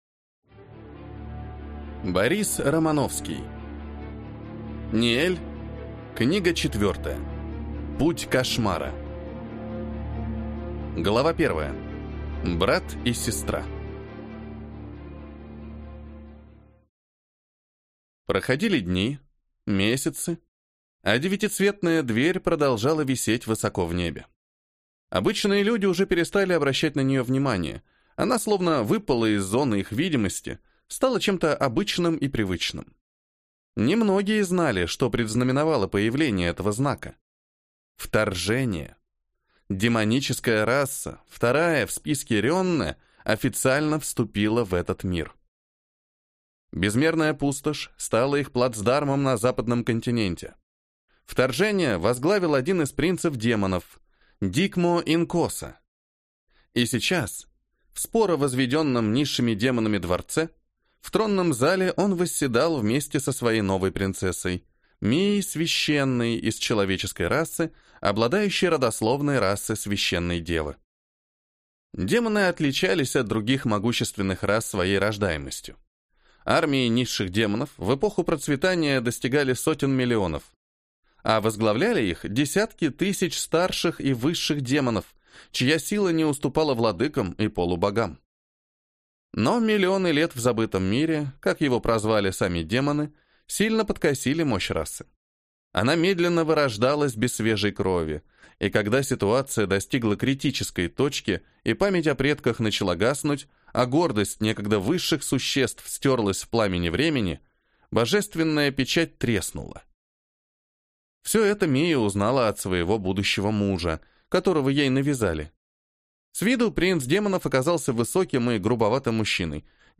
Аудиокнига Путь Кошмара | Библиотека аудиокниг